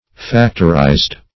Factorized - definition of Factorized - synonyms, pronunciation, spelling from Free Dictionary
factorize \fac"tor*ize\, v. t. [imp. & p. p. Factorized